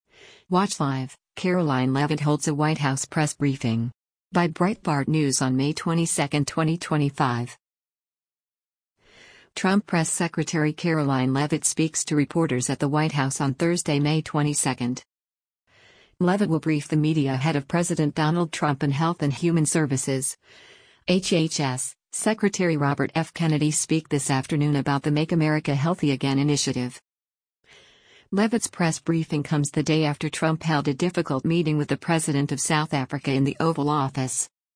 Watch Live: Karoline Leavitt Holds a White House Press Briefing
Trump Press Secretary Karoline Leavitt speaks to reporters at the White House on Thursday, May 22.